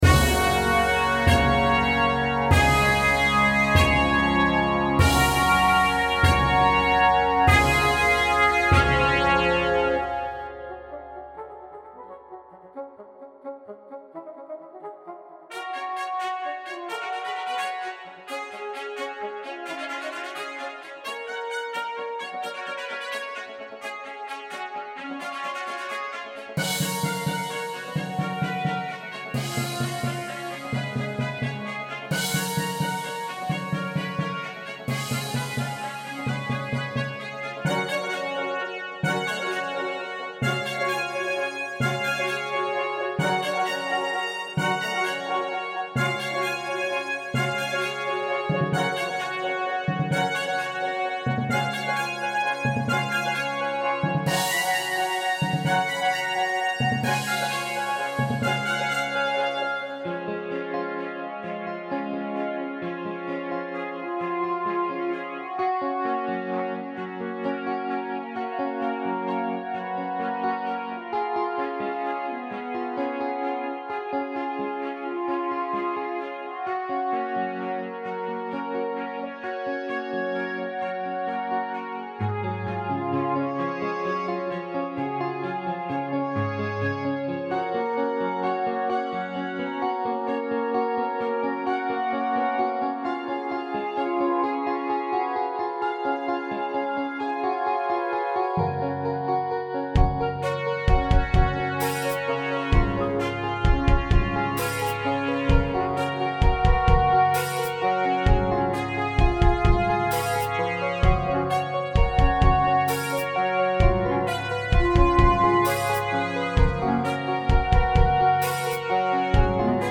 Life (instrumental) - Orchestrated: 29th August - 18th September 2011.
This was a rather grand attempt to both musically sum up life and write an orchestral sounding opening.
There are obvious Beethoven influences such as the ostentatious use of the timpani.